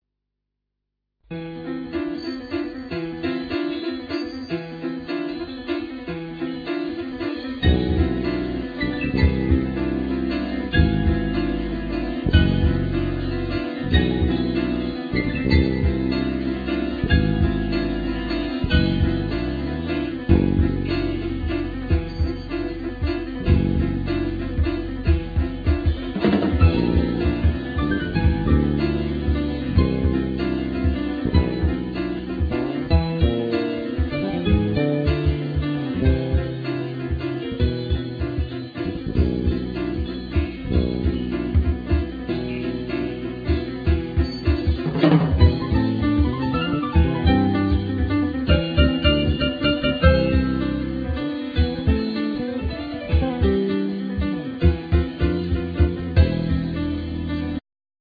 Piano
Double Bass
Drums
Clarinet,Bass Clarinet
Bandoneon
Violin
Voice
Saxophone